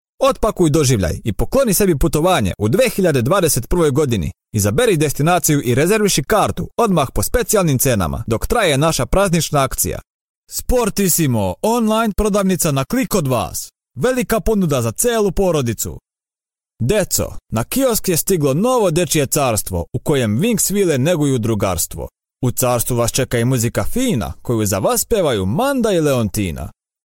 塞尔维亚语样音试听下载
塞尔维亚语配音员（男2）